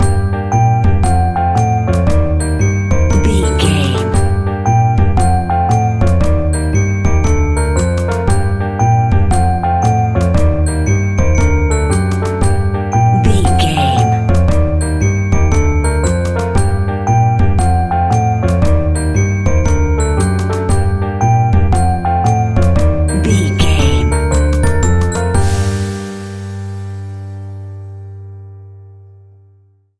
Aeolian/Minor
scary
ominous
dark
eerie
double bass
electric organ
piano
drums
electric piano
instrumentals
horror music